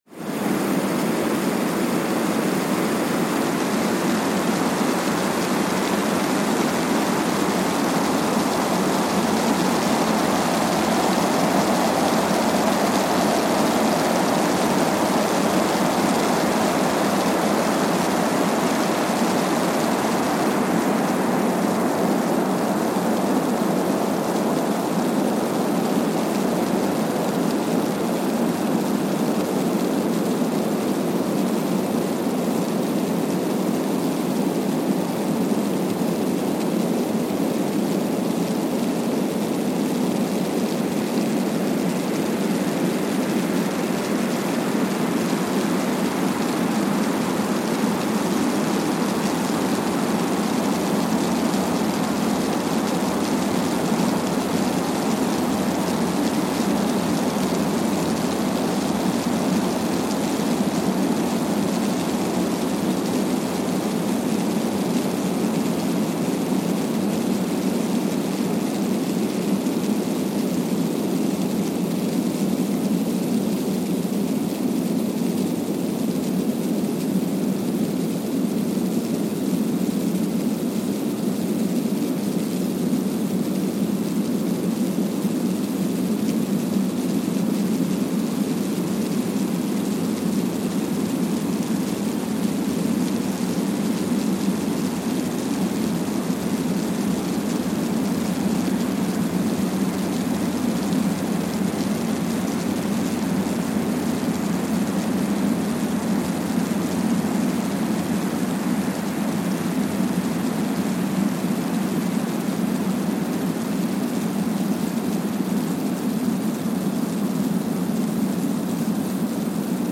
Kwajalein Atoll, Marshall Islands (seismic) archived on October 18, 2020
No events.
Sensor : Streckeisen STS-5A Seismometer
Speedup : ×1,000 (transposed up about 10 octaves)
Loop duration (audio) : 05:45 (stereo)
SoX post-processing : highpass -2 90 highpass -2 90